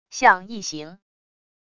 像异形wav音频